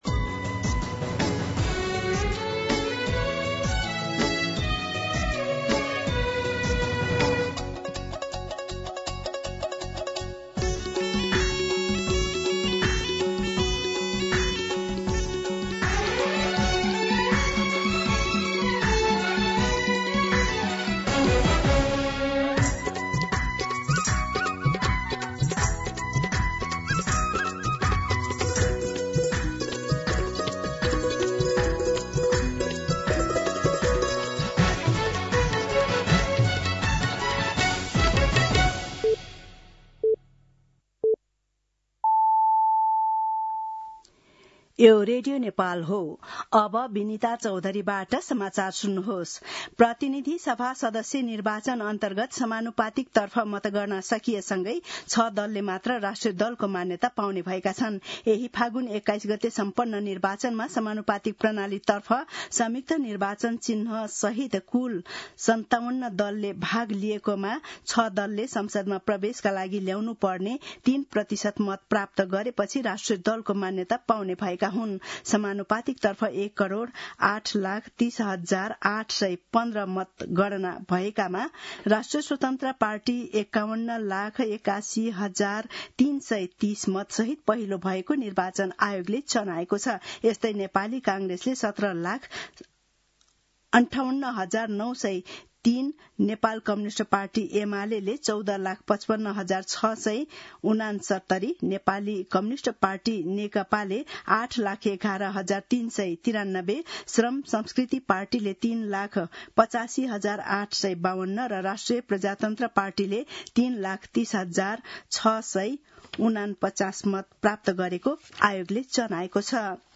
दिउँसो १ बजेको नेपाली समाचार : २८ फागुन , २०८२